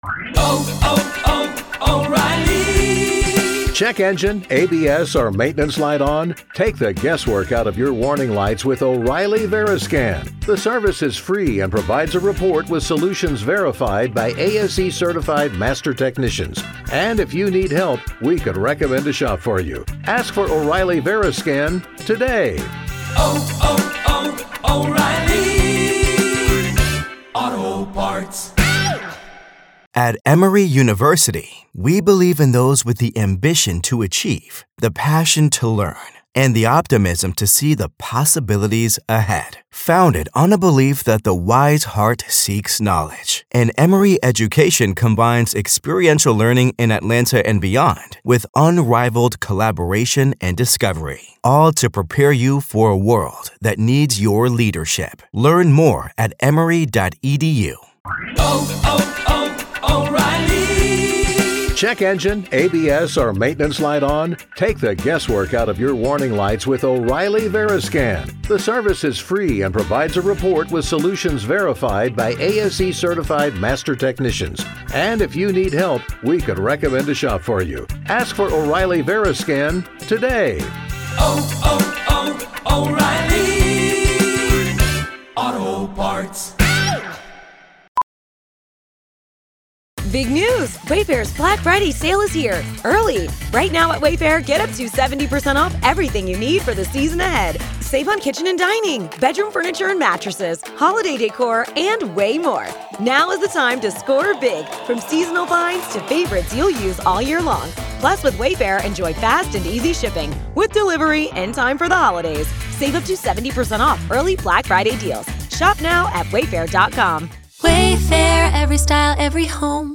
From the psychology of conspiracies to the shady behavior of investigators, this conversation exposes how modern true crime isn’t just about guilt or innocence—it’s about identity.